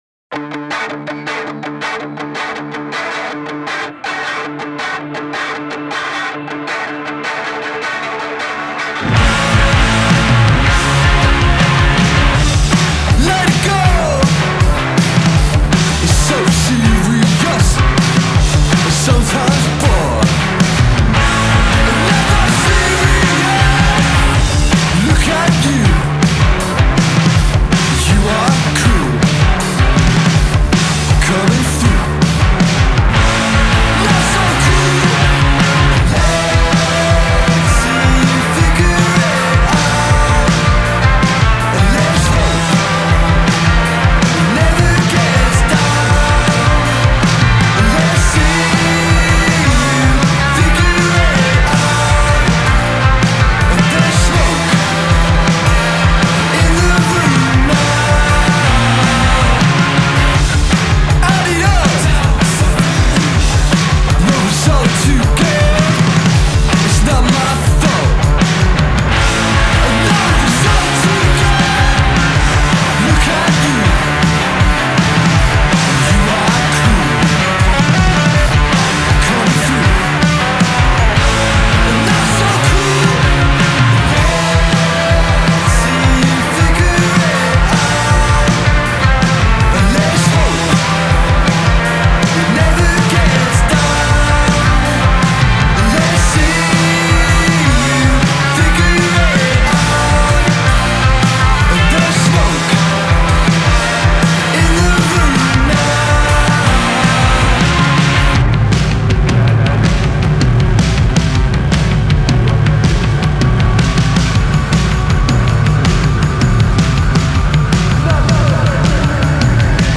release their new spikey, punk-fulled tune
Welsh /Gloucestershire quartet